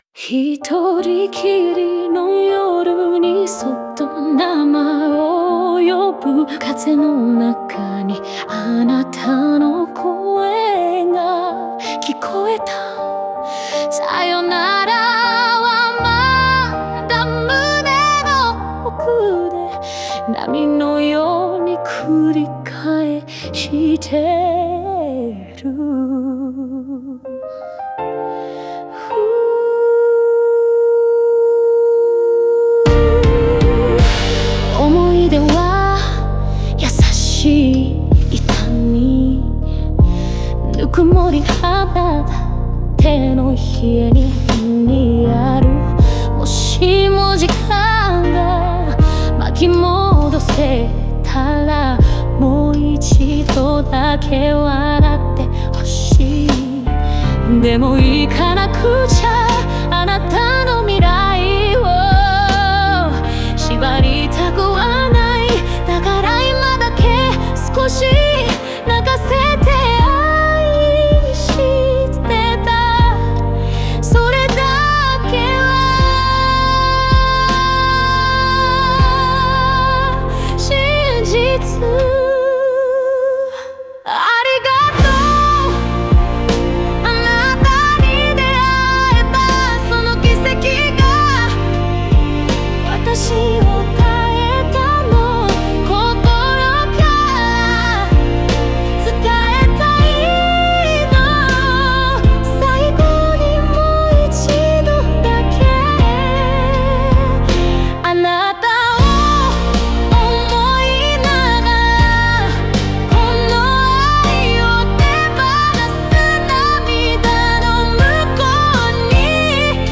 静かな始まりから感情が高まり、壮大なサビで愛の深さが爆発する構成となっており、切なさと力強さが交錯します。